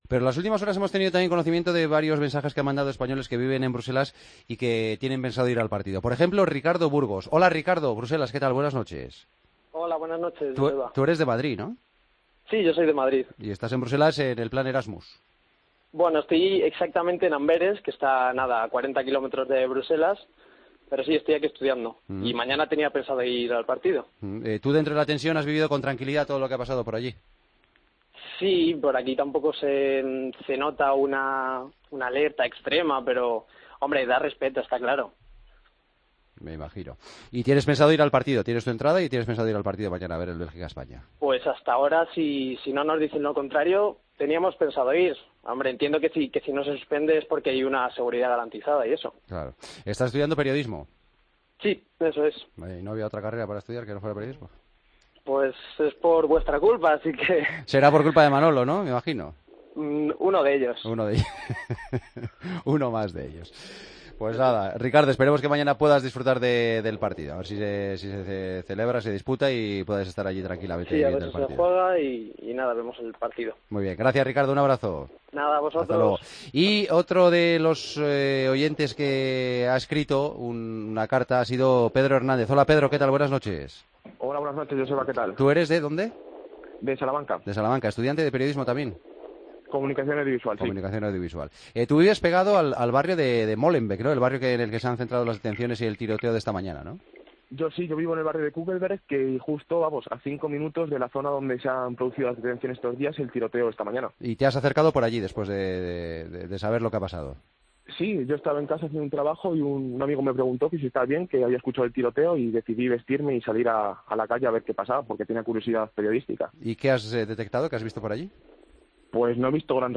En el momento en que El Partido de las 12 charlaba con dos estudiantes españoles de Erasmus en Bélgica, que iban a acudir al partido, la RFEF le confirma a Manolo Lama la suspensión del amistoso que debería haberse celebrado este martes.